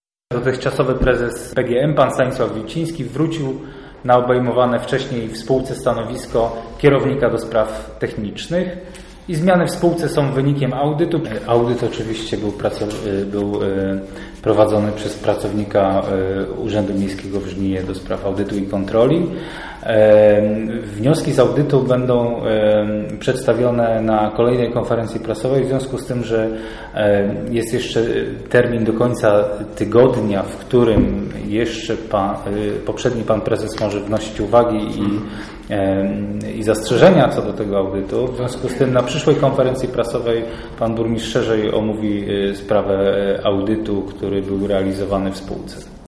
Jak poinformował nas na konferencji prasowej